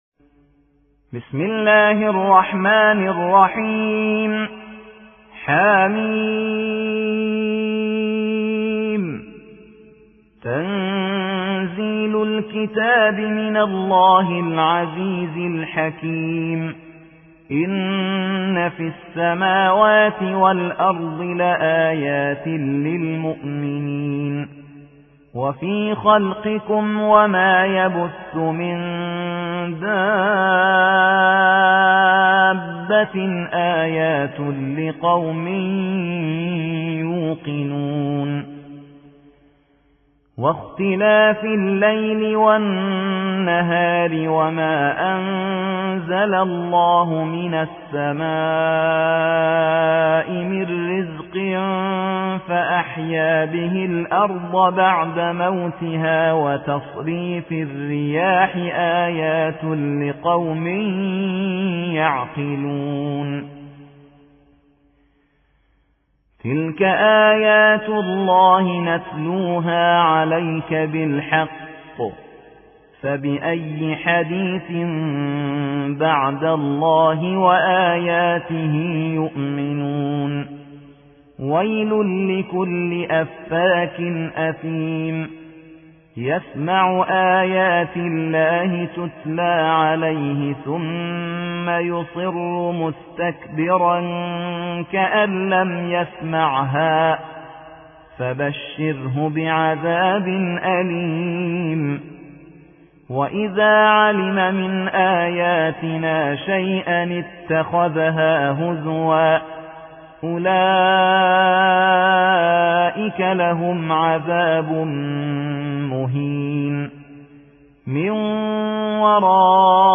45. سورة الجاثية / القارئ